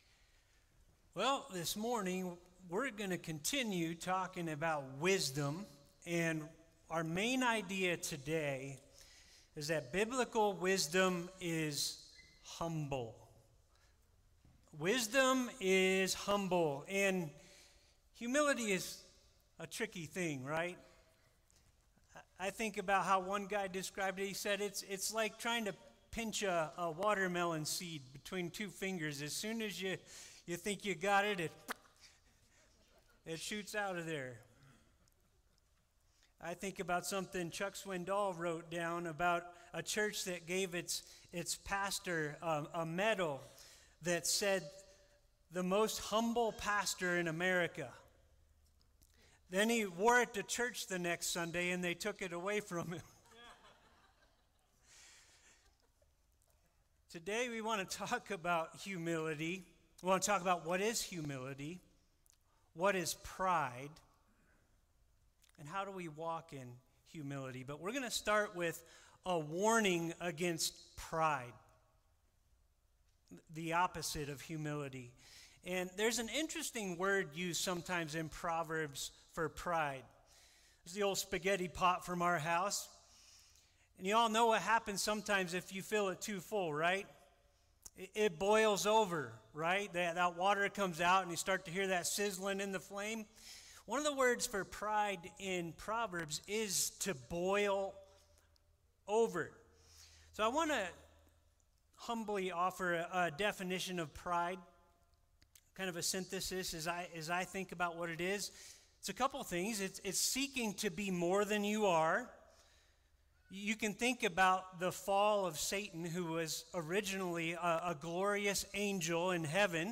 Today’s message seeks to answer those questions and encourage you on the road to humility.